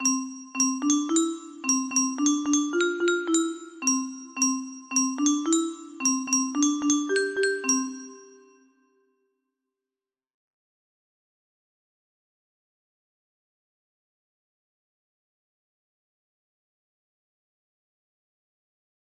04Matarile paper music box melody